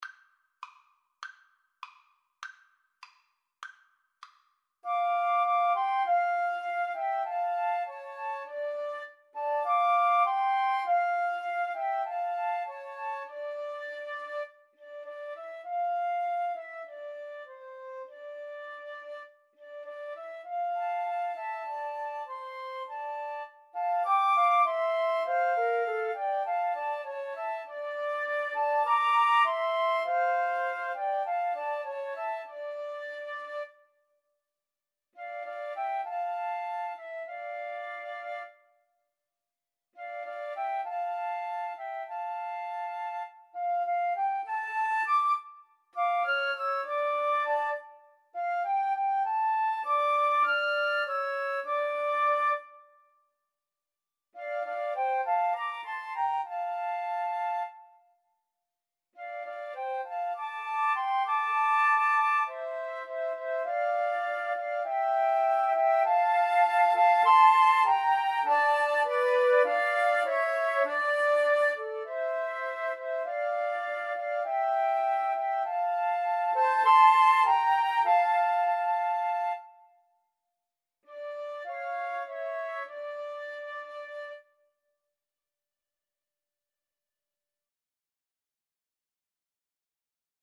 Moderato
2/4 (View more 2/4 Music)
Arrangement for Flute Trio
Classical (View more Classical Flute Trio Music)